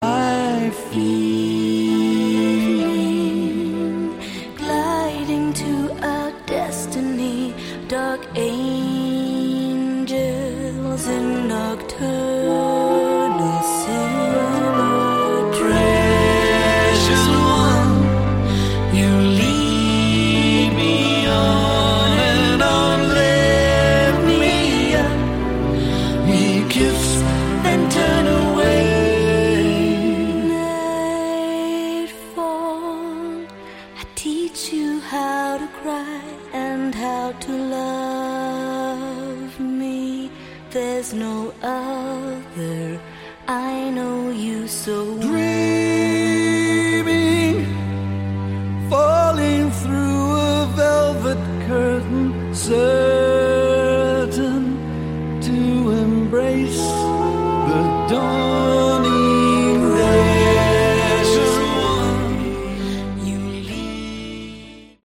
Category: Melodic Rock
vocals, bass
keyboards
guitar
cello
drums
guest harp
guest female voice